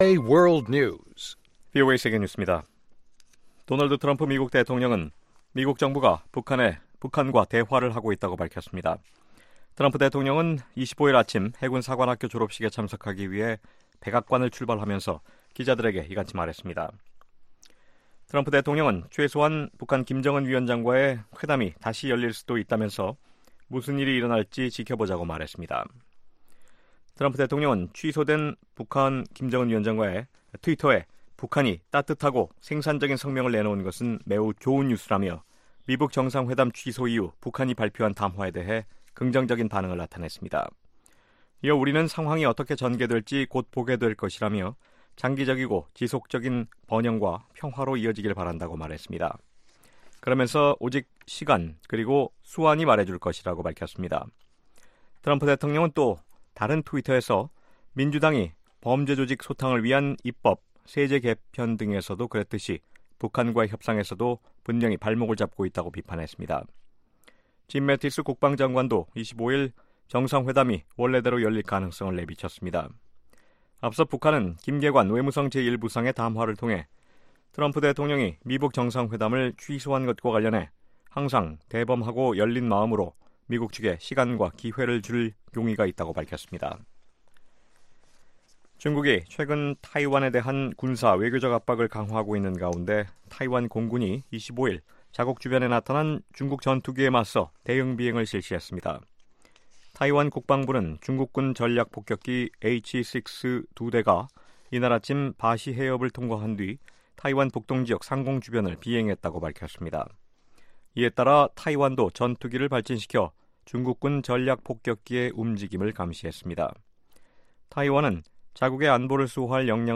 VOA 한국어 아침 뉴스 프로그램 '워싱턴 뉴스 광장' 2018년 5월 26일 방송입니다. 도널드 트럼프 대통령은 미국 정부가 북한과 대화를 재개했다고 밝혔습니다. 백악관은 북한이 많은 약속들을 어기고 비핵화에 대한 성실성을 보이지 않았기 때문에 미북회담을 취소했다고 설명했습니다.